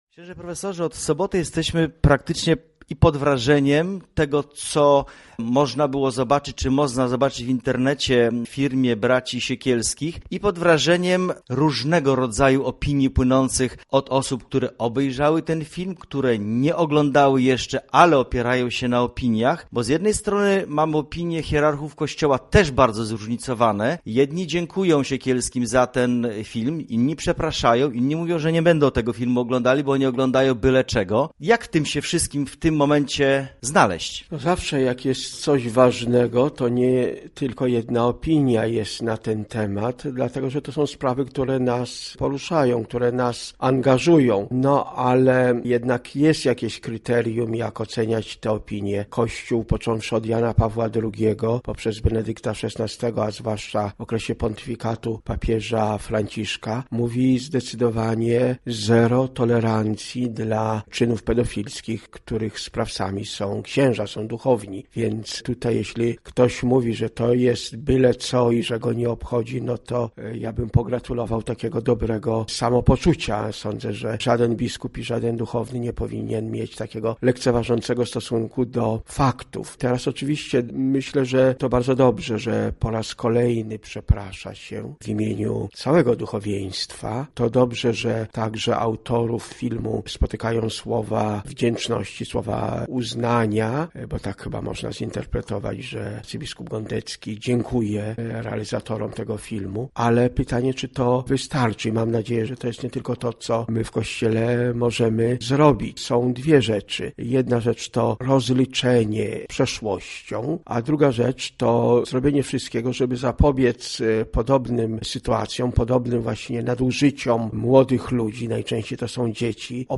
W zamian proponujemy rozmowę z księdzem